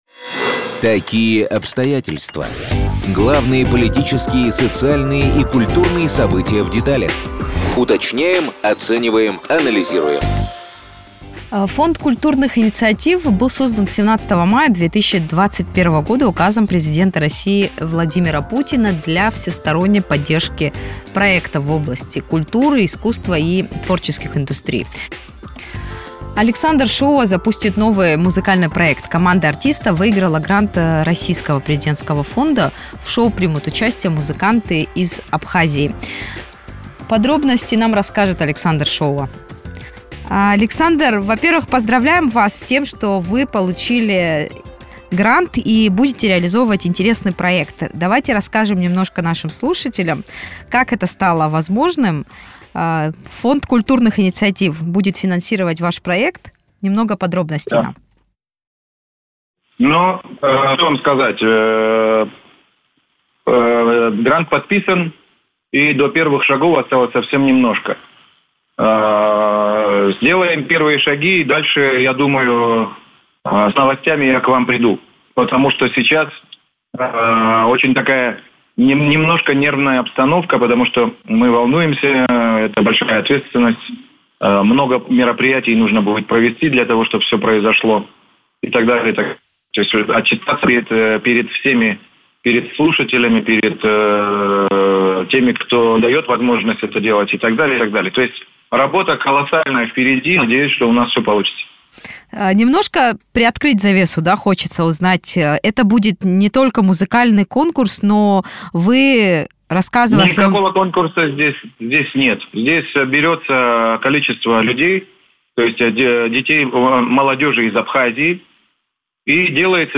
Им будут записывать песни, снимать клипы, продвигать их на главных российских площадках. Подробности Александр Шоуа сообщил в интервью радио Sputnik.